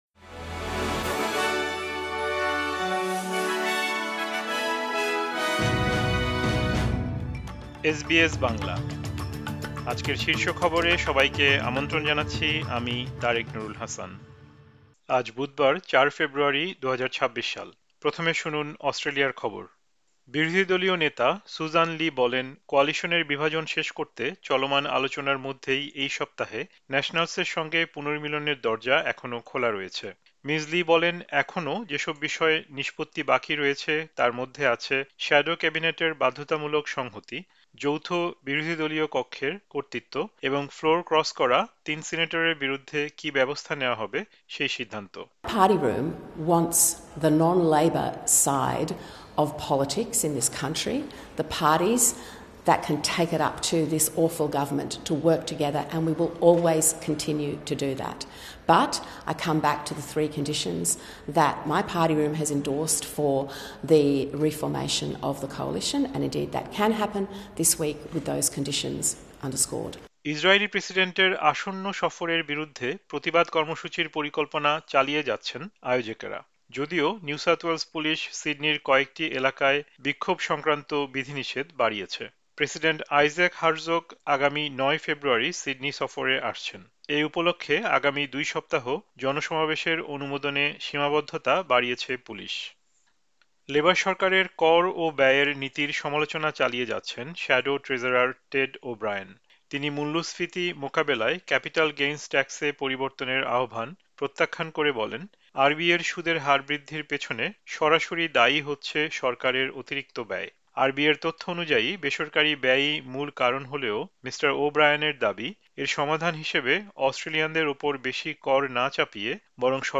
অস্ট্রেলিয়ার জাতীয় ও আন্তর্জাতিক সংবাদের জন্য আজ ৪ ফেব্রুয়ারি, ২০২৬ এর এসবিএস বাংলা শীর্ষ খবর শুনতে উপরের অডিও-প্লেয়ারটিতে ক্লিক করুন।